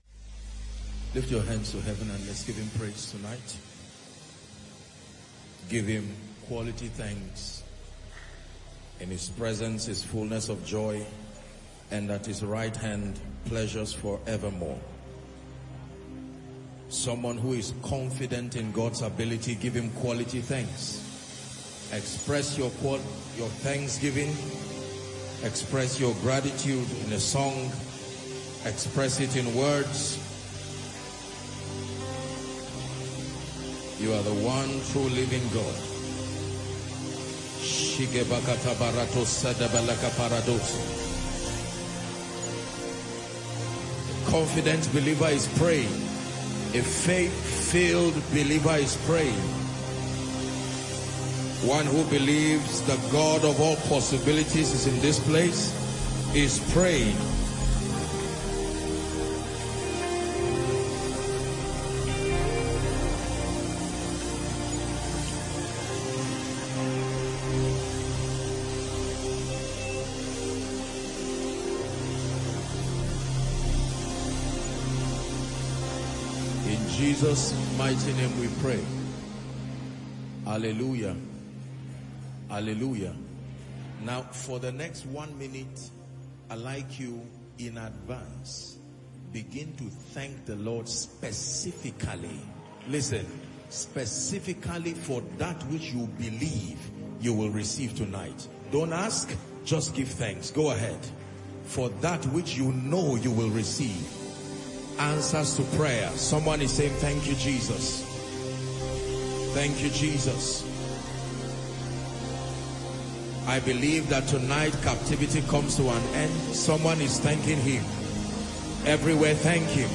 Held on January 26, 2025, this highly anticipated service was characterized by vibrant worship, a compelling Word from God, prophetic declarations, and undeniable manifestations of His power. For thousands in attendance and many more joining online, the service provided an unforgettable encounter with the supernatural.